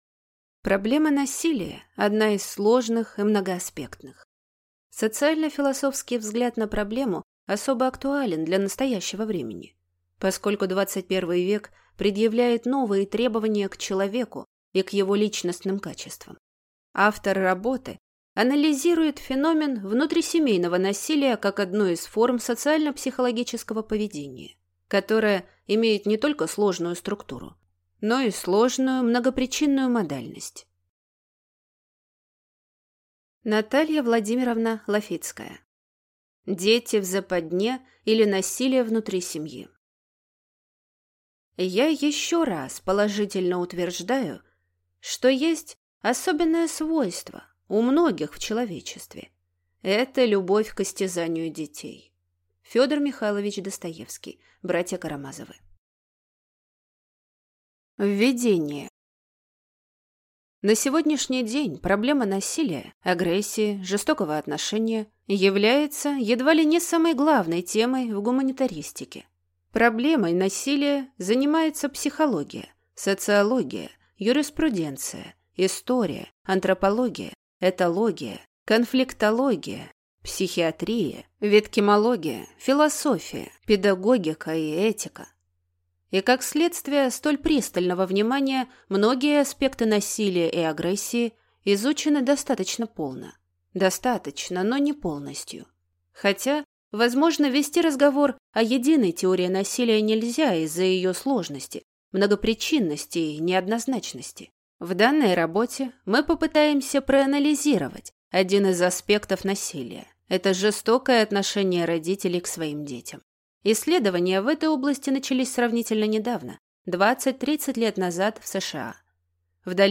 Аудиокнига Дети в западне, или Насилие внути семьи | Библиотека аудиокниг